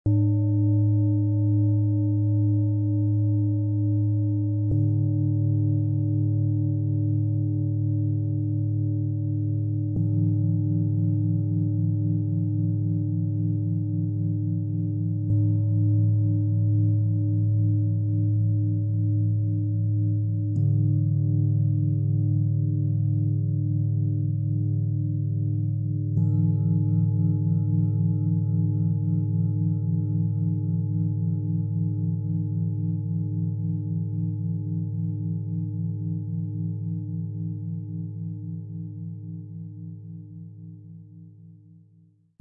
Aus der Tiefe des Inneren Kraft & Inspiration holen und mit Bauchgefühl, Herz & Energie ins Leben bringen - Set aus 3 Planetenschalen, für bewusste Klangmassagen und achtsame Körperarbeit Ø 18,4 -21,5 cm, 2,62 kg
Dieses Set verbindet tiefe Weisheit, gelebte Kraft und sanfte Herzöffnung zu einer harmonischen Schwingung, die Mut und Gefühl in Einklang bringt.
Tiefster Ton: Neptun - Tiefe Intuition und innere Weisheit
Mittlerer Ton: Mars - Mut, Tatkraft und Klarheit
Höchster Ton: Hopi, Mond, Mond - Herzöffnung und emotionale Balance
Im Sound-Player - Jetzt reinhören kann der Original-Ton dieser drei handgefertigten Schalen angehört werden. Ihre Schwingungen entfalten eine wohltuende Tiefe und feine Resonanz.